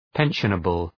{‘penʃənəbəl}